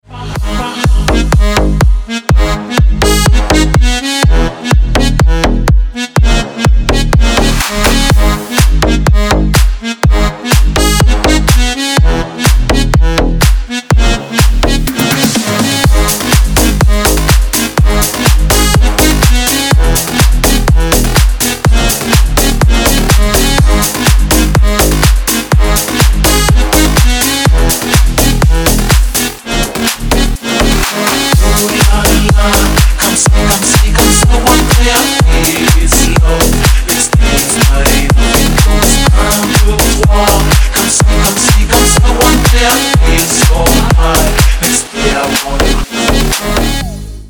Dance рингтоны